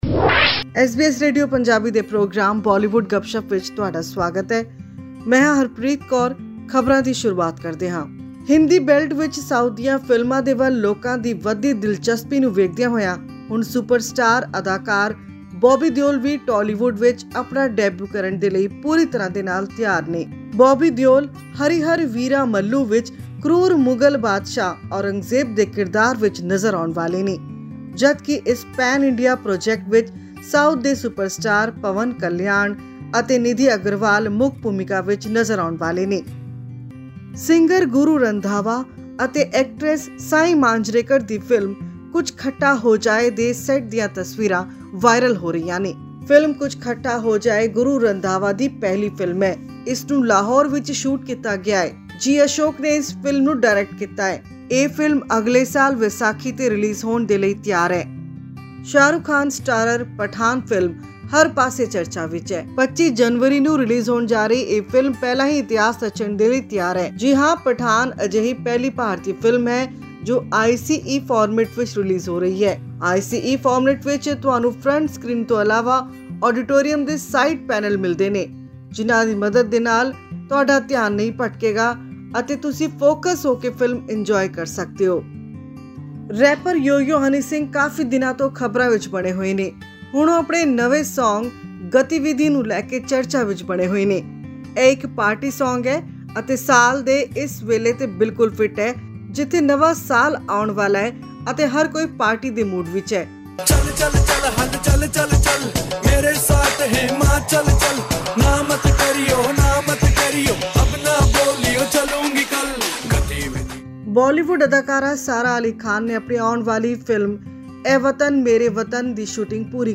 Famous singer Guru Randhawa, who has given many hit songs over the years, is now ready to showcase his acting talent with the upcoming film 'Kuch Khattaa Ho Jaay.' This and more in our weekly news segment from the world of movies and music.